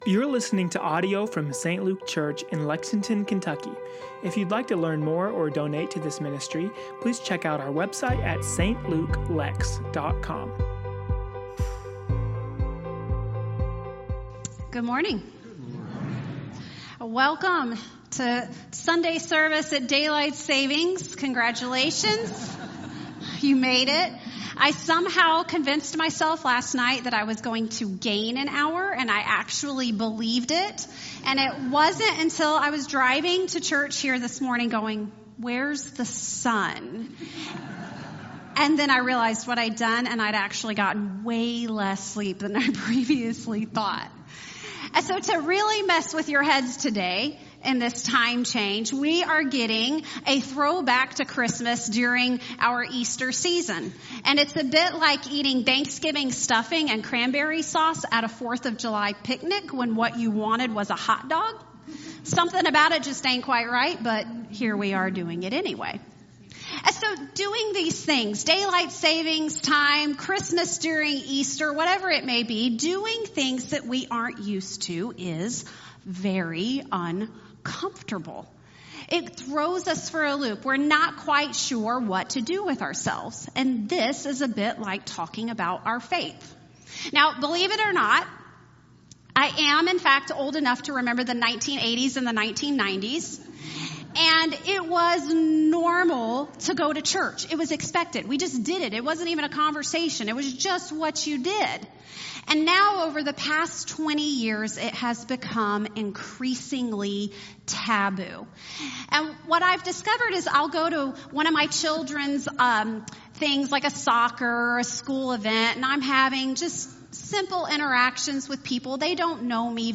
St. Luke Church Lexington - Sermons & Teachings LifeSavers: Was Jesus Born of a Virgin?